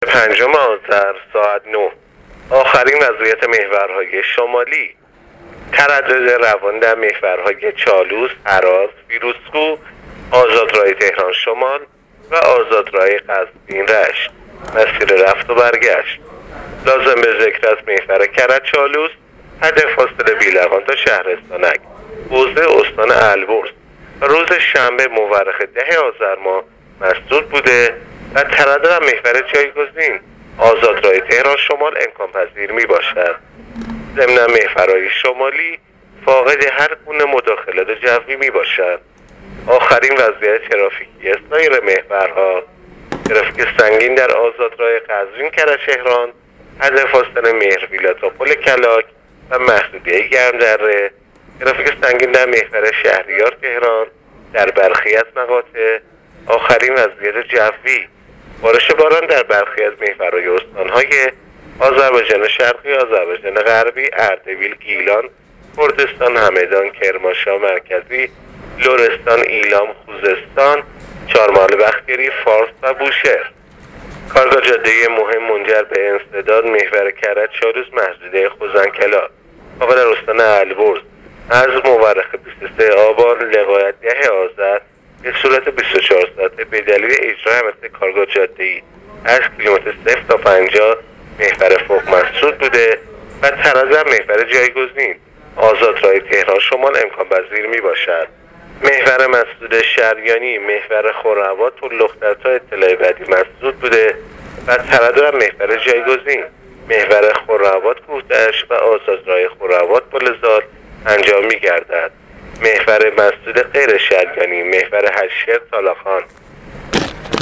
گزارش رادیو اینترنتی از آخرین وضعیت ترافیکی جاده‌ها تا ساعت ۹ پنجم آذر؛